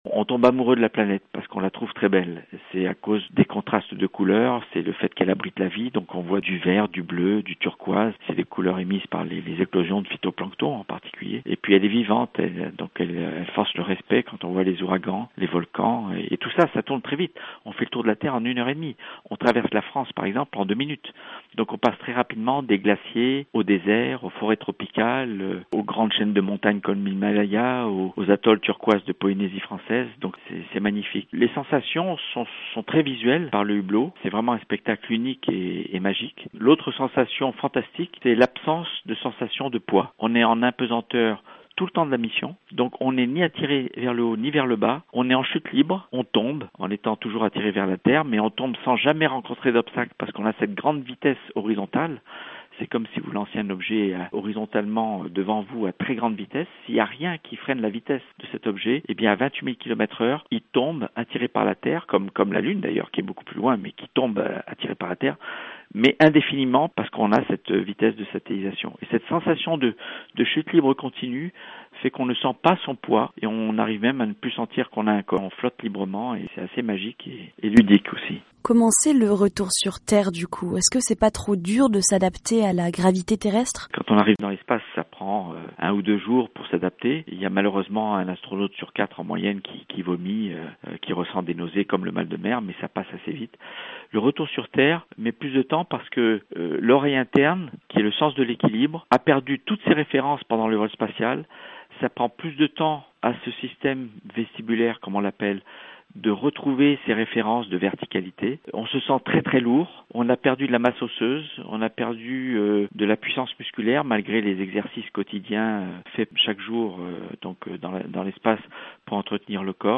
Jean Francois Clervoy est un astronaute français qui a exécuté trois missions pour le compte de l’agence nationale américaine de l’aéronautique et de l’espace (NASA) dans les années 90. Il fait aujourd’hui partie des trois français qui sont encore actifs dans le corps des astronautes de l’Agence Spatiale Européenne. Il a accordé cette interview à VOA Afrique.